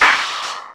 70TECHNOSD-R.wav